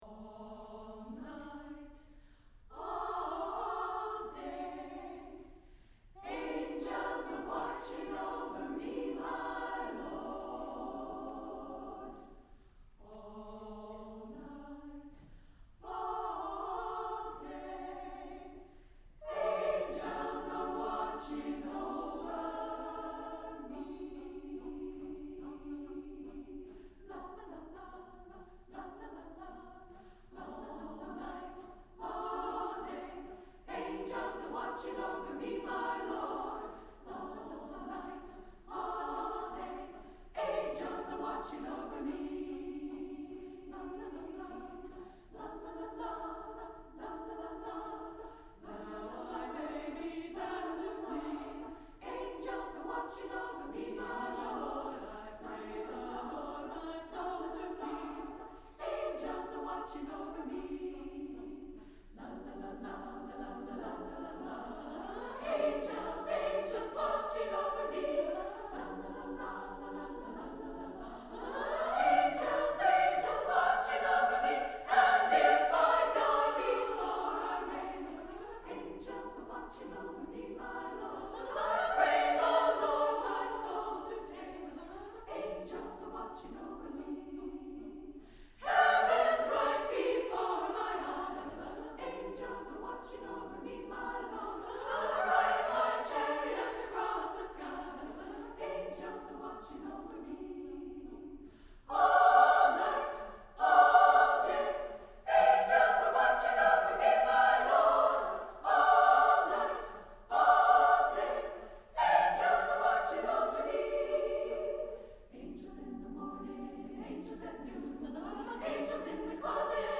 for SSAA Chorus (2000-02)
The Spirit of Women is a set of three songs for Women's Chorus, a cappella.